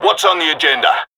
UNC HA Bot留言 | 贡献2022年7月15日 (五) 22:38的版本 （分类替换 - 替换『Category:红色警戒3语音』为『Category:守护者坦克娘语音』）